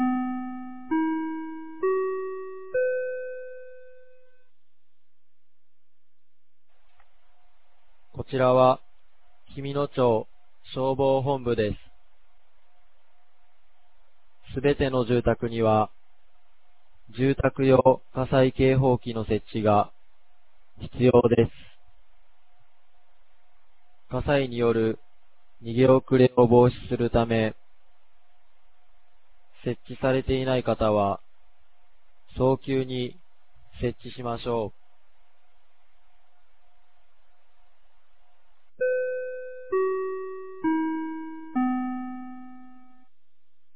2026年04月18日 16時00分に、紀美野町より全地区へ放送がありました。